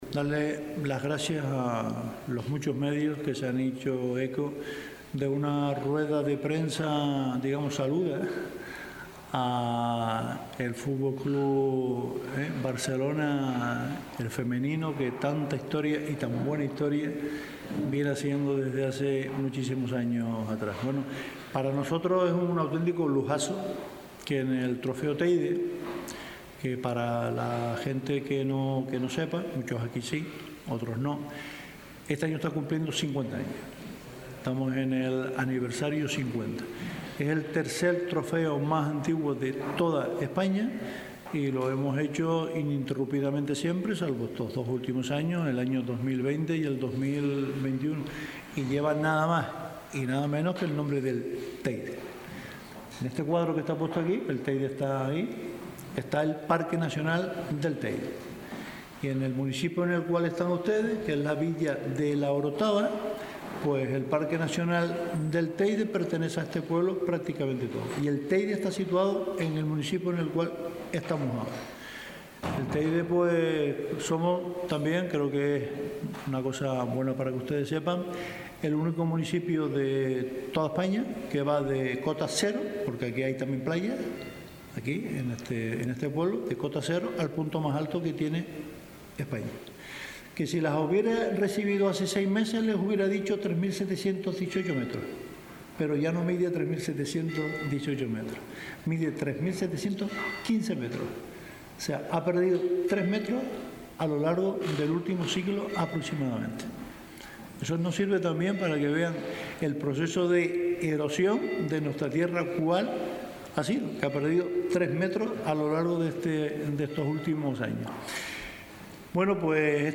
La Orotava. Recepción a las jugadoras del FC Barcelona.
la-orotava-recepcion-a-las-jugadoras-del-fc-barcelona.mp3